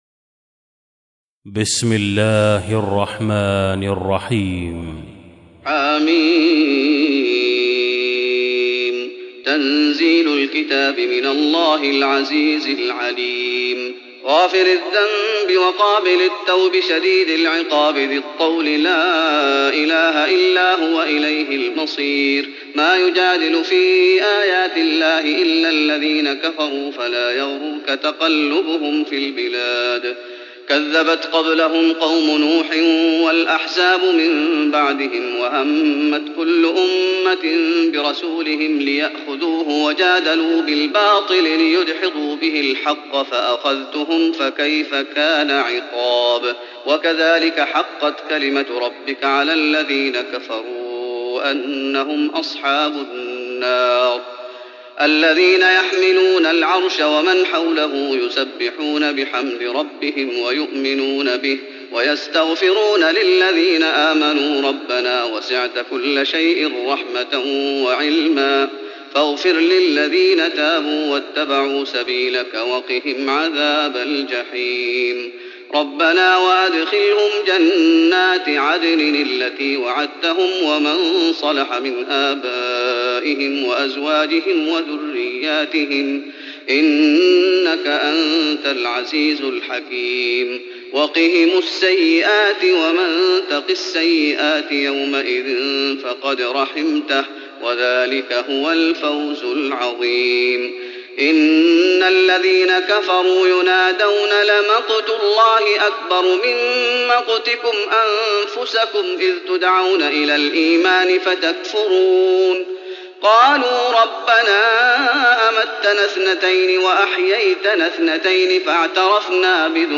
تراويح رمضان 1415هـ من سورة غافر (1-40) Taraweeh Ramadan 1415H from Surah Ghaafir > تراويح الشيخ محمد أيوب بالنبوي 1415 🕌 > التراويح - تلاوات الحرمين